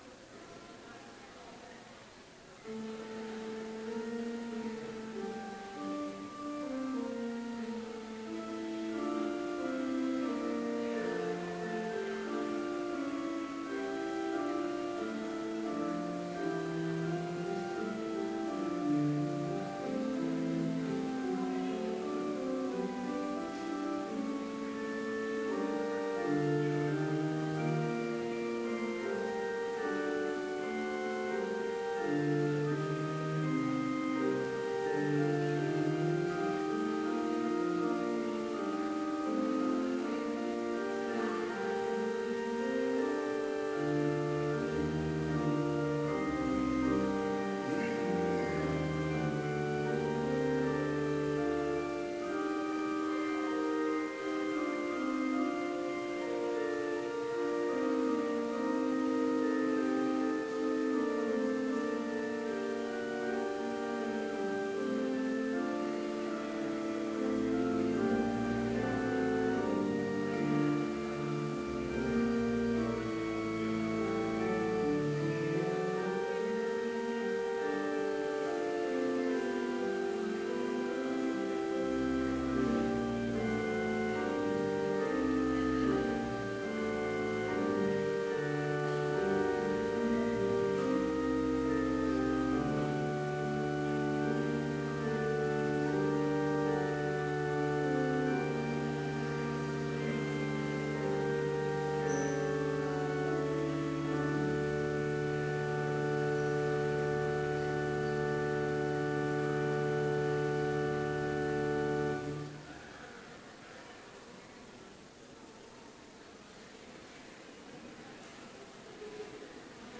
Complete service audio for Chapel - April 20, 2016
Prelude
Postlude